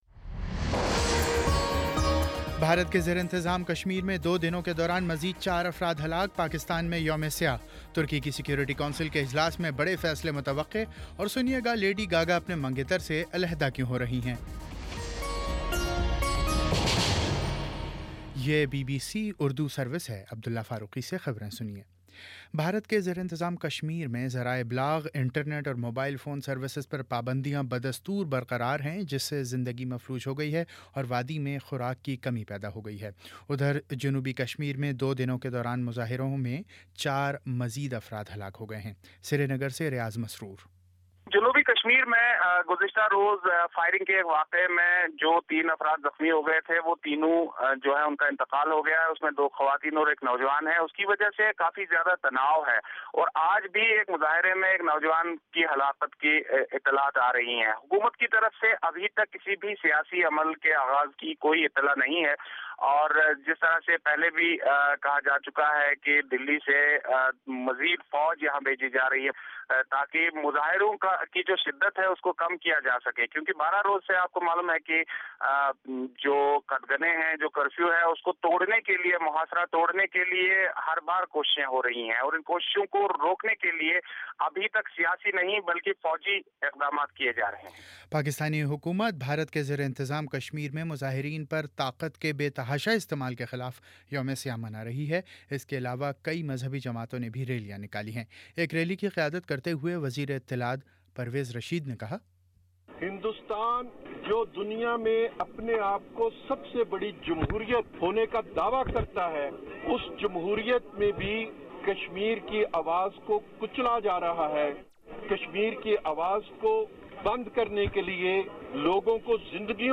جولائی 20 : شام پانچ بجے کا نیوز بُلیٹن